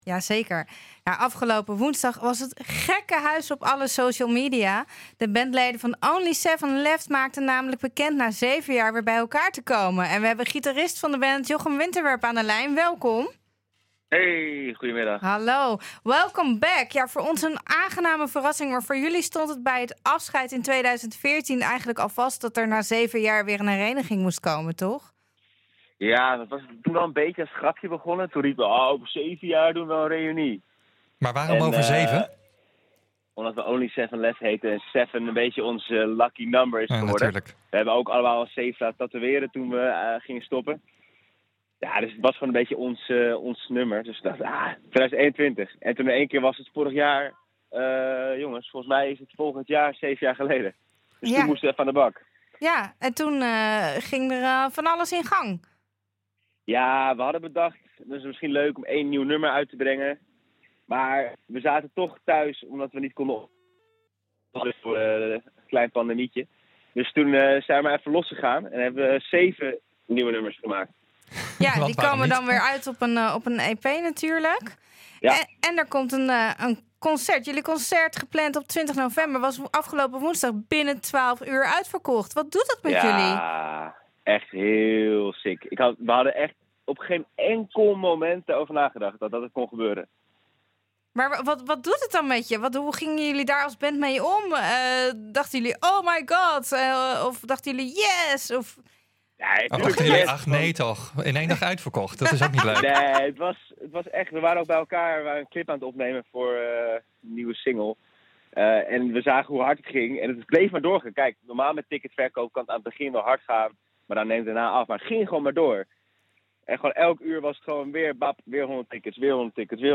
aan de lijn met de primeur van de eerste single!